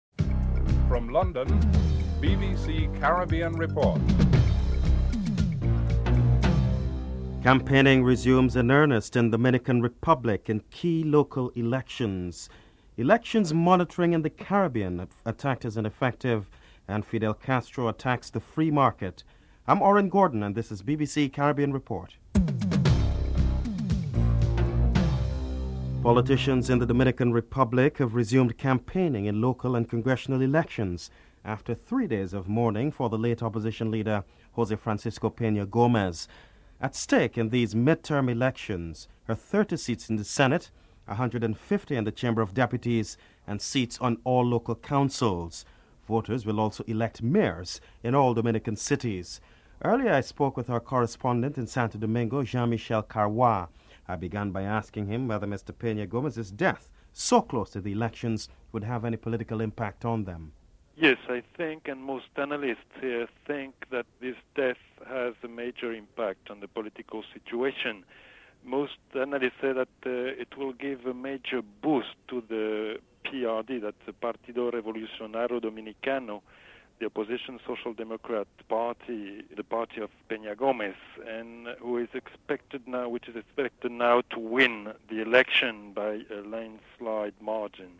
Prime Minister Kenny Anthony comment on the issue (12:56-15:15)